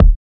Kick Murdah 1.wav